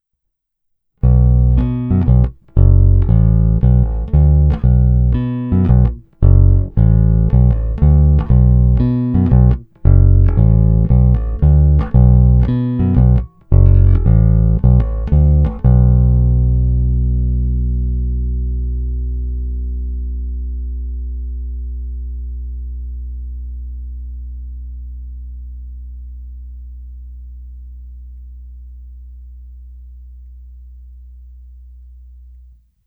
Zvuk je tlustý, zvonivý vrčák s okamžitým nástupem pevného tónu.
Není-li uvedeno jinak, následující nahrávky jsou provedeny rovnou do zvukovky a dále kromě normalizace ponechány bez úprav.
Tónová clona vždy plně otevřená.
Hra mezi krkem a snímačem